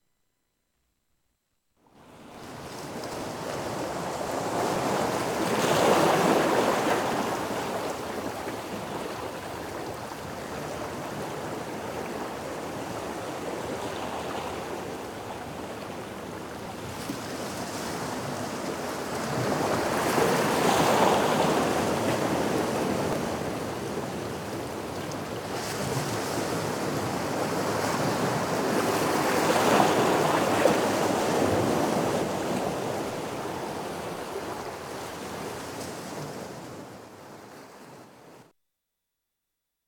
Eveil-Corpus-Vagues.mp3